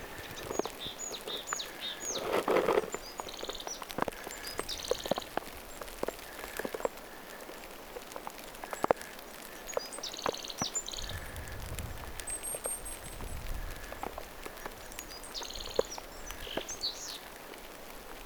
Kuukkeliruokinnalla lauloi hömötiaislintu
hömötiaislinnun laulua
homotiaisen_laulua.mp3